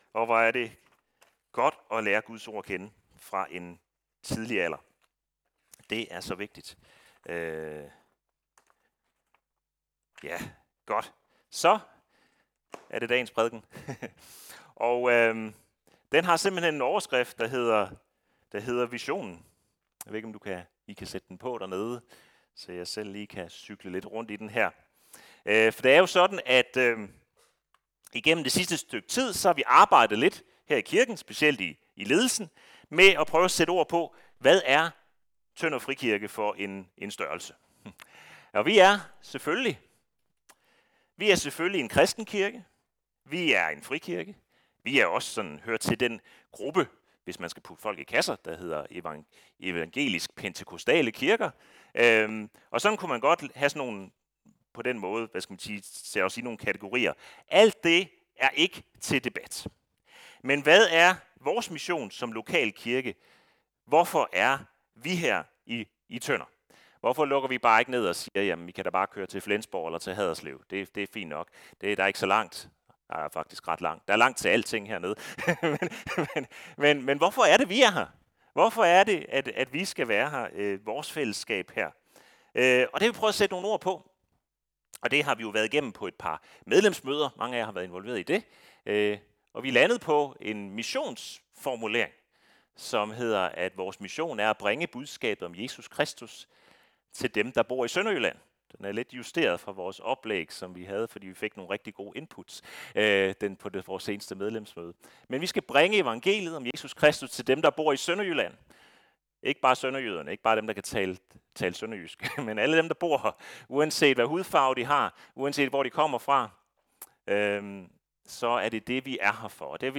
Normal gudstjeneste uden noget specielt som højtid, nadver eller Supersøndag